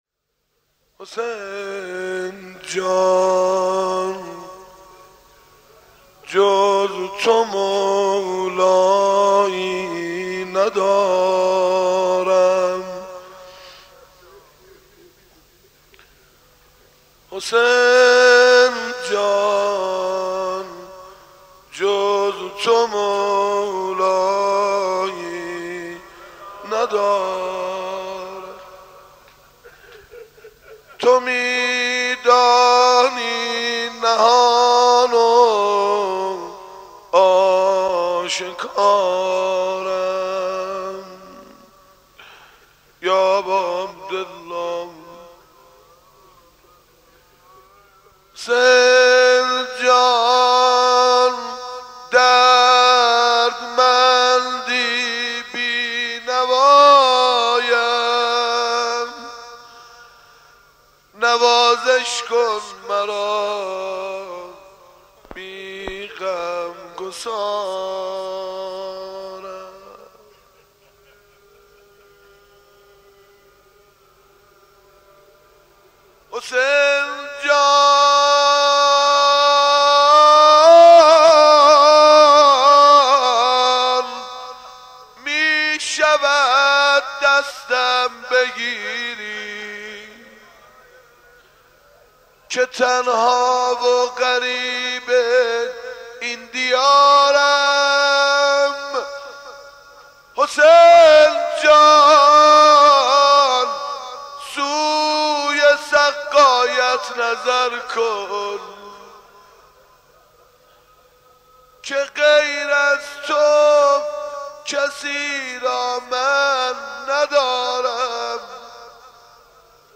دعای کمیل